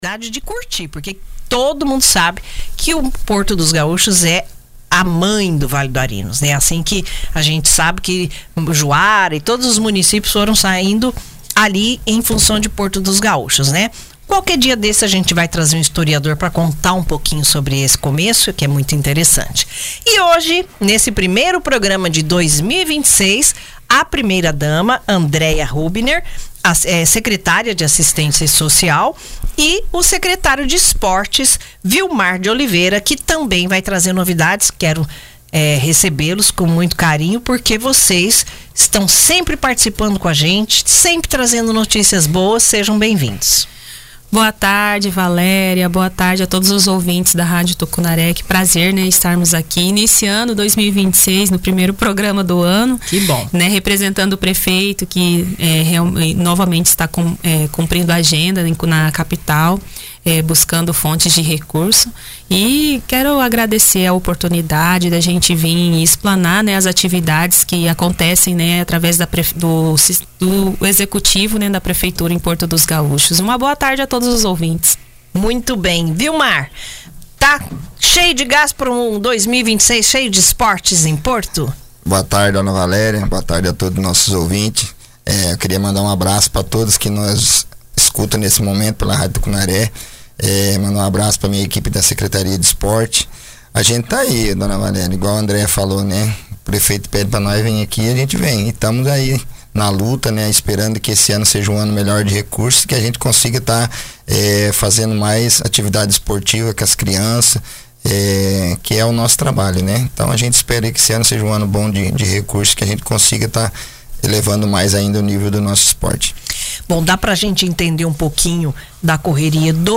Porto dos Gaúchos abriu oficialmente o calendário institucional de 2026 com a realização do primeiro programa “De Frente com o Prefeito”, transmitido pela Rádio Tucunaré 89,3 FM nesta sexta-feira, dia 13.
Representando o chefe do Executivo, que cumpre agenda em Cuiabá em busca de recursos, participaram a primeira-dama e secretária de Assistência Social, Andreia Hubner, e o secretário municipal de Esportes, Vilmar de Oliveira. A reportagem da Rádio Tucunaré e site Acesse Notícias apurou os principais pontos discutidos durante a entrevista.